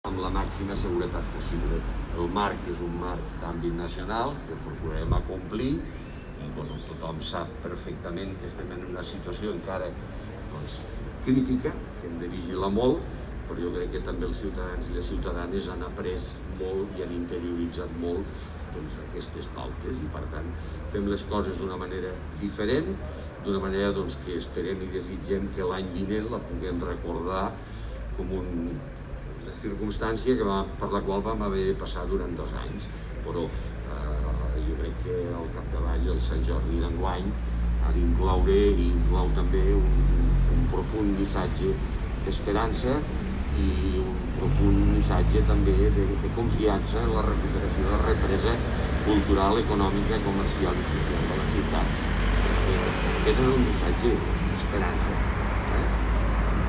tall-de-veu-de-lalcalde-de-lleida-miquel-pueyo-sobre-la-diada-de-sant-jordi